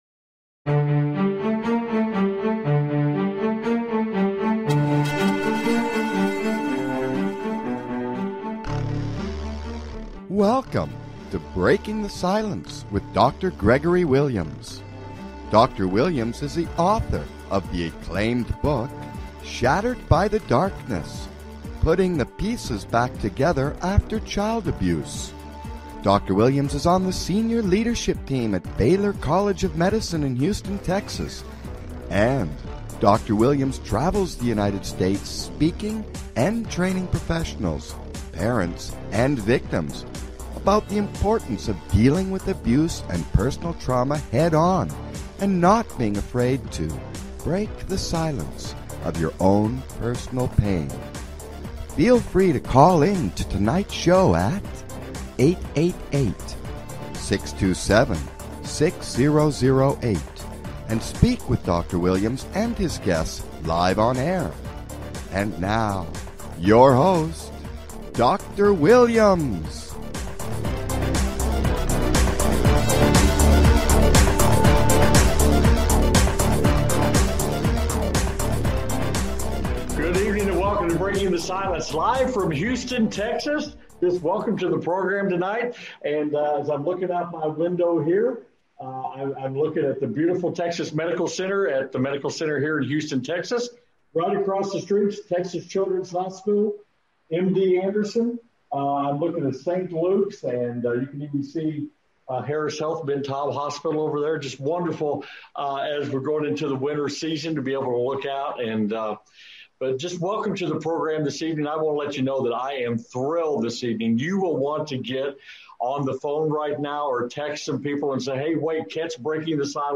Guest, Dr Bruce D Perry, discussing child trauma and neuroscience